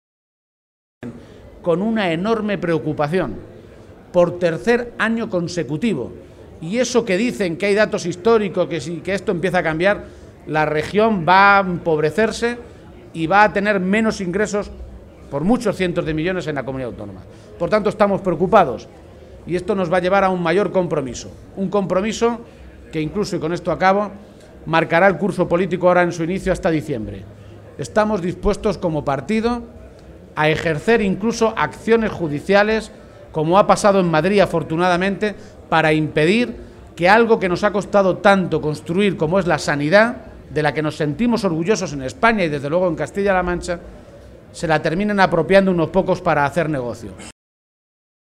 En la atención a medios de comunicación, García Page advirtió que el PSOE de Castilla-La Mancha está dispuesto a seguir el camino marcado por los socialistas en Madrid «para impedir que algo que nos ha costado tanto construir como es la sanidad de la que nos sentimos tan orgullosos se la acaben apropiando unos pocos para hacer negocio».